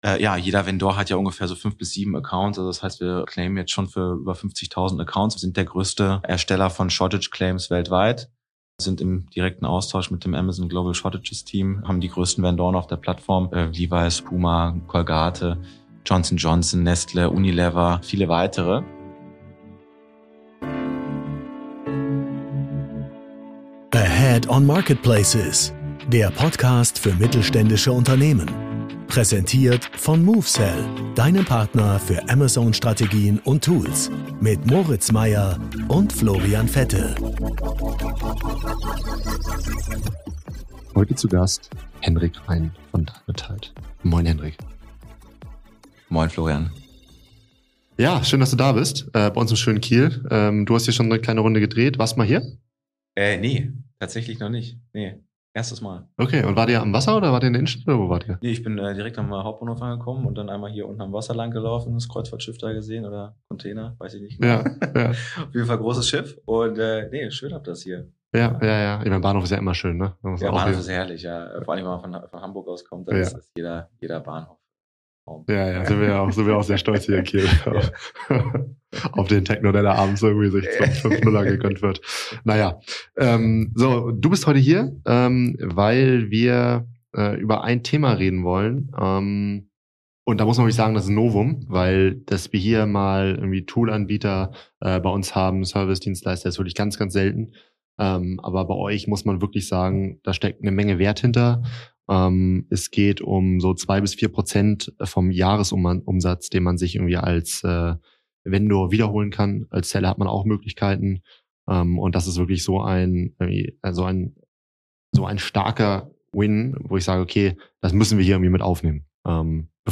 Beschreibung vor 4 Monaten Ein Gespräch über Millionenbeträge im Amazon-Kosmos, verlorene Ware, unsichtbare Abrechnungsfehler – und die Frage: Wie viel Geld verschenken Vendoren jährlich, ohne es zu merken?